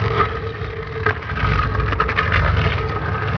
1 channel
scrape0g.wav